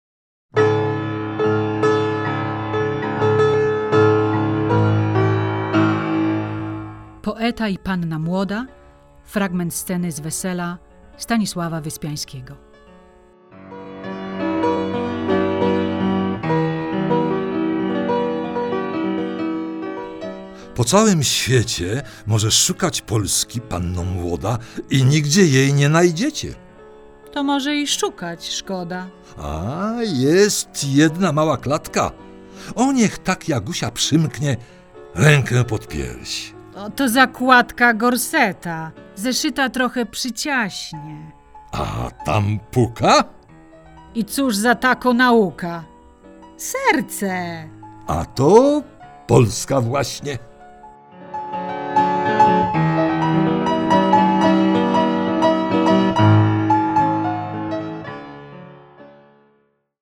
Wykonawcy Scenariusz, oprawa muzyczna, efekty i realizacja projektu
Recytacje